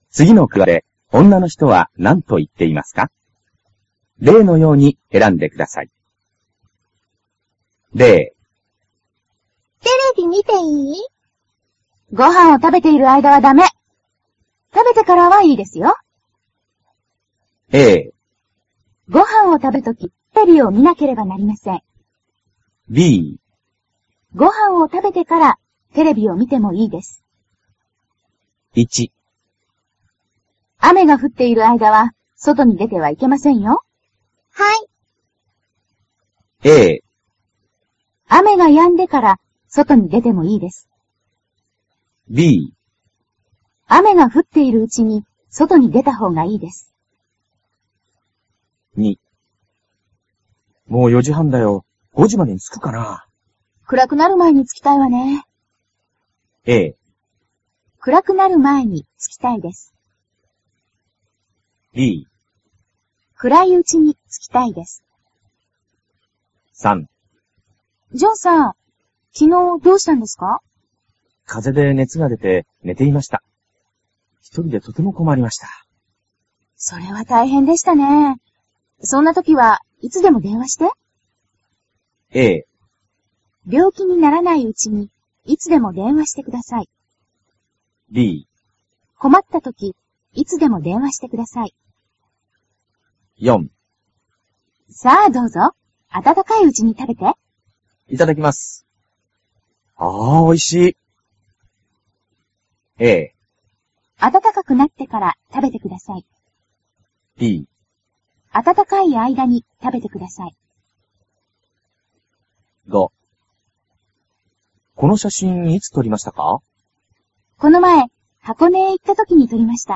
Người phụ nữ nói gì trong các đoạn hội thoại sau đây?